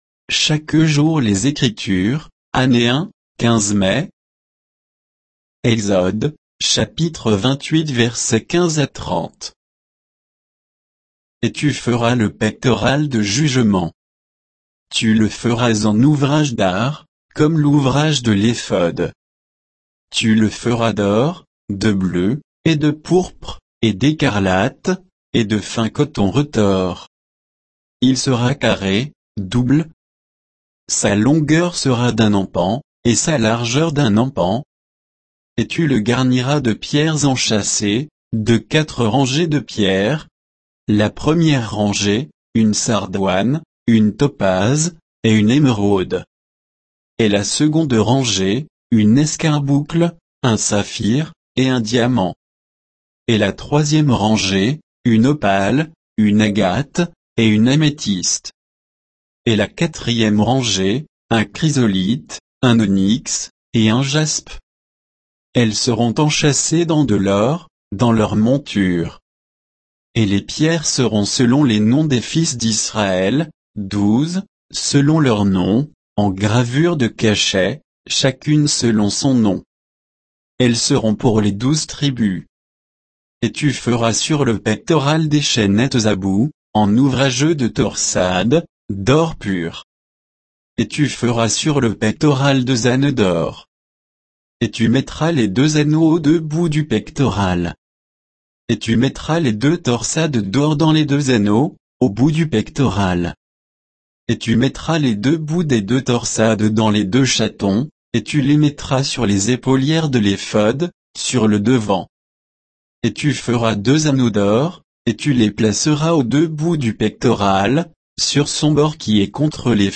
Méditation quoditienne de Chaque jour les Écritures sur Exode 28